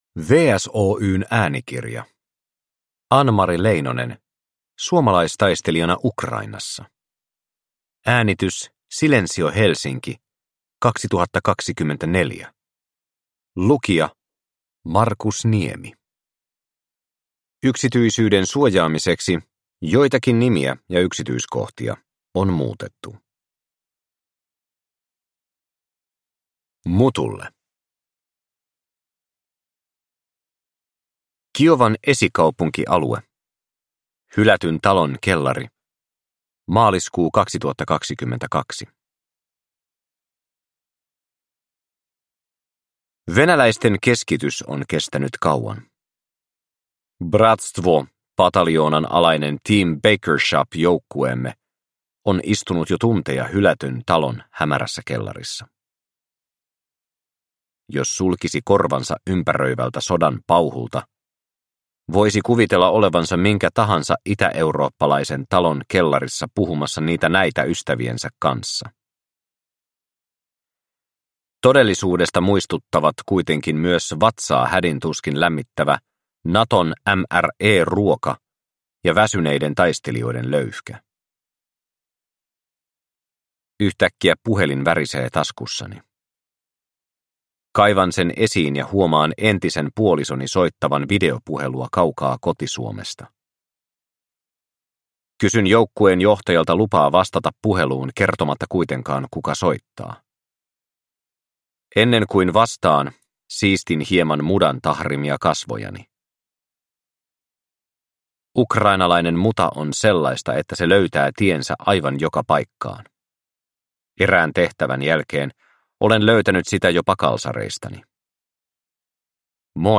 Suomalaistaistelijana Ukrainassa – Ljudbok